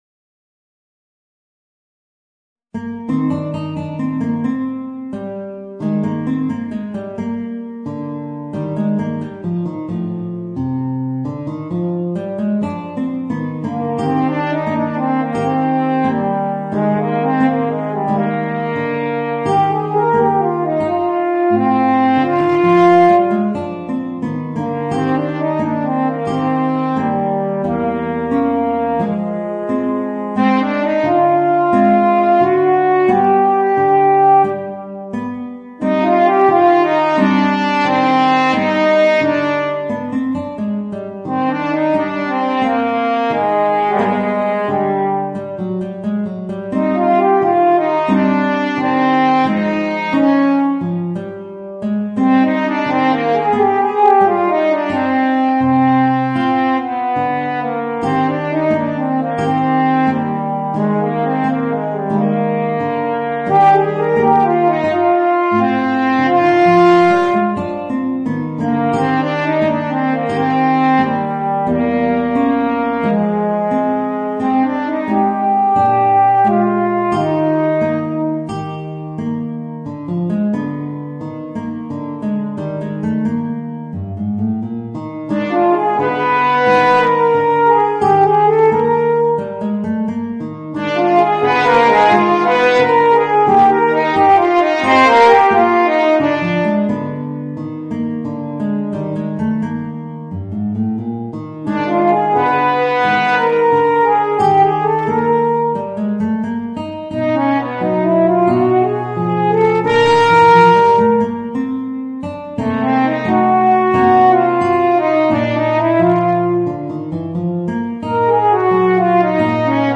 Voicing: Guitar and Eb Horn